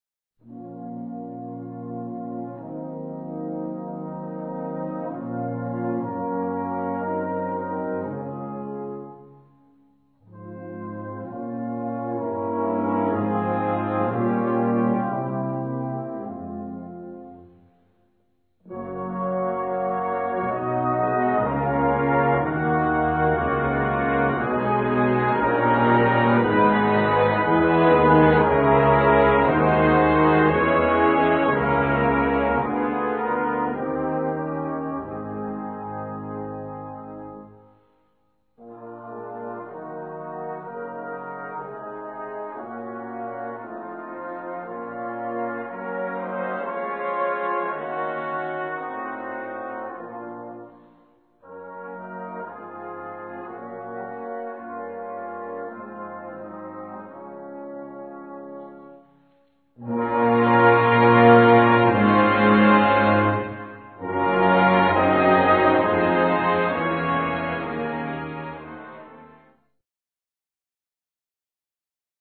3:03 Minuten Besetzung: Blasorchester PDF